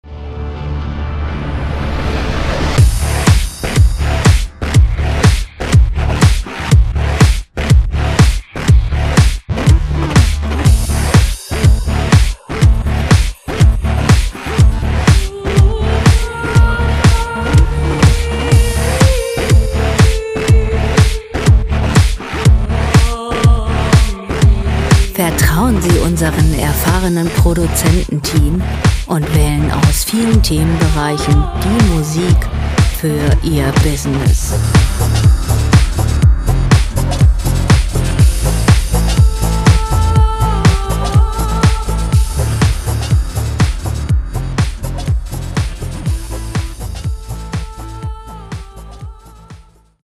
Elektronische Musik
Musikstil: Dark Tech House
Tempo: 122 bpm
Tonart: D-Moll
Charakter: aggressiv, massiv
Instrumentierung: Synthesizer, Vocals